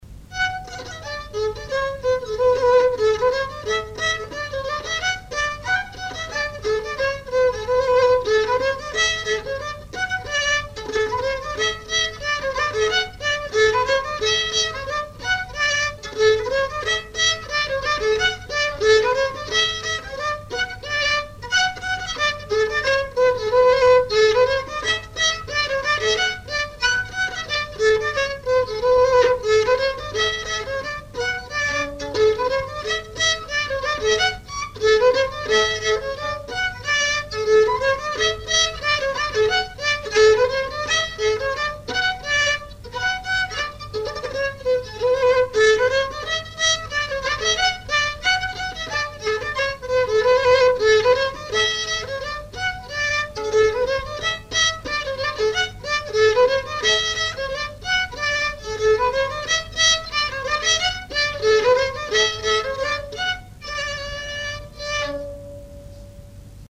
danse : scottich trois pas
enregistrements du Répertoire du violoneux
Pièce musicale inédite